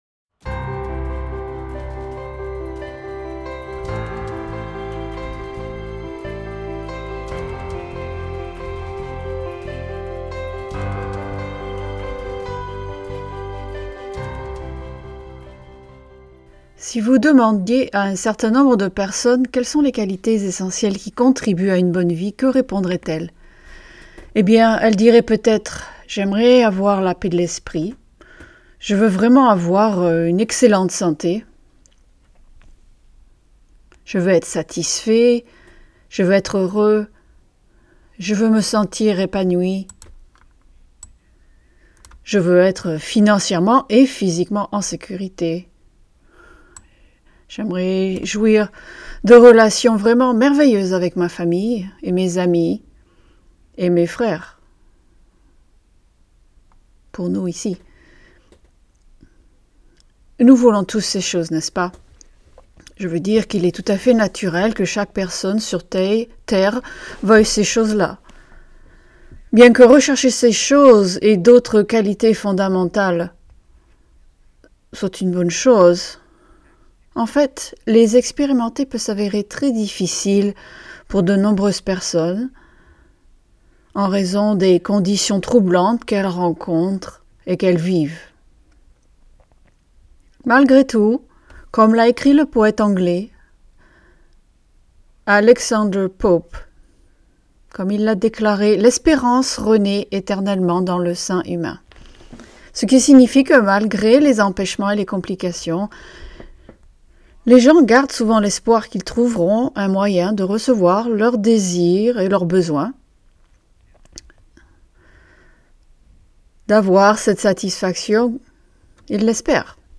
Sermons
Given in Bordeaux